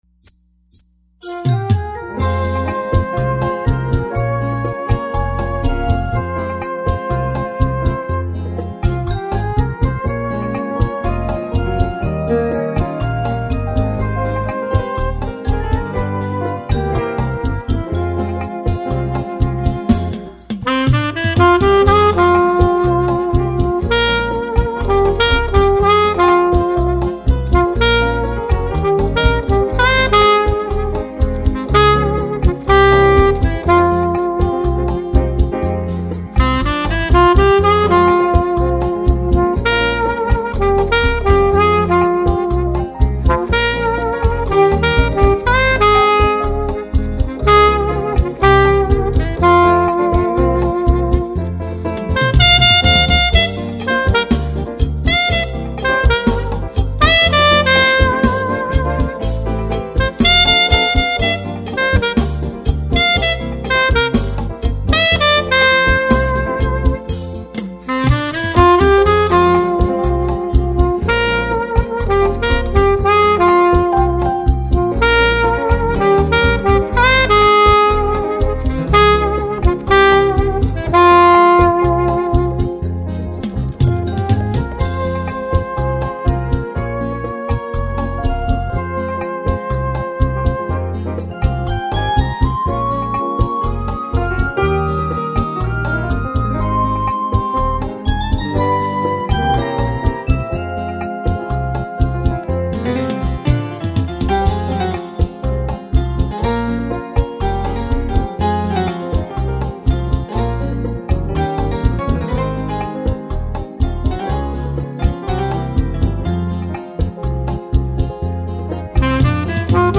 827   03:14:00   Faixa:     Bolero
Sax Alto
Teclados
Guitarra